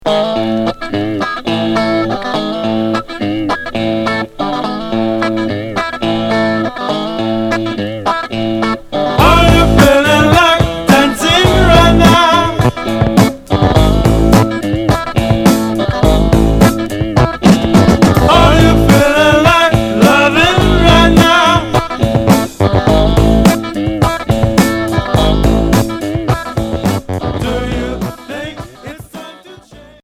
Blues rock Premier 45t retour à l'accueil